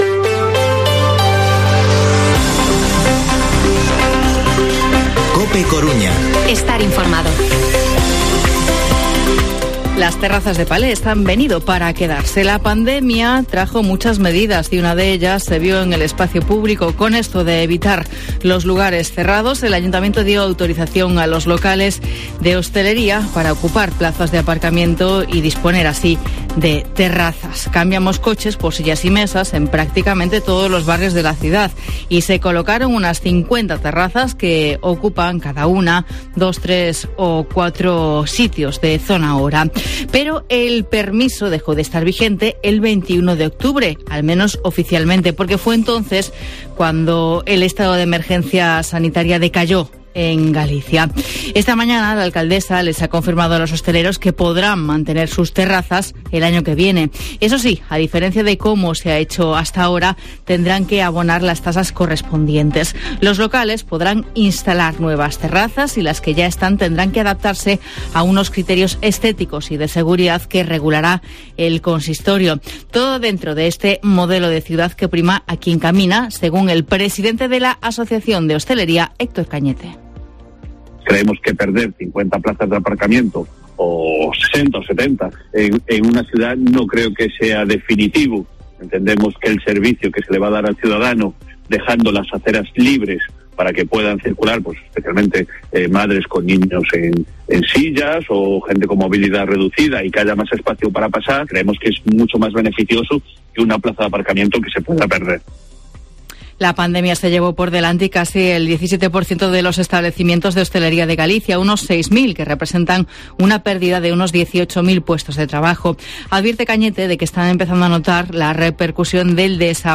Informativo Mediodía COPE Coruña lunes, 8 de noviembre de 2021 14:20-14:30